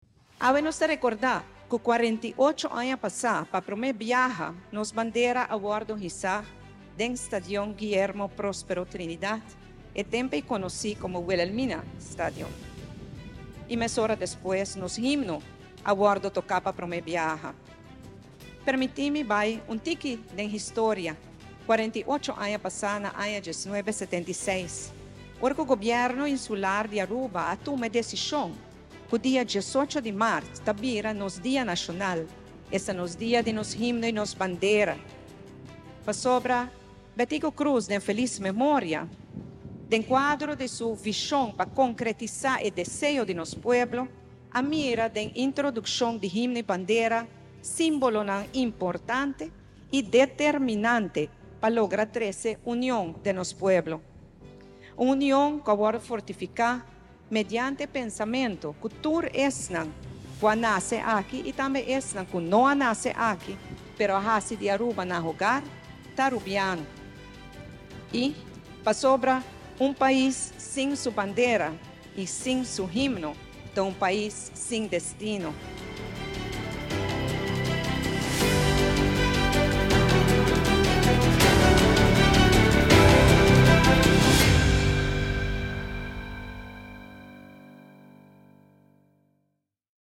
Discurso di Prome Minister Evelyn Wever-Croes Dia di Himno y Bandera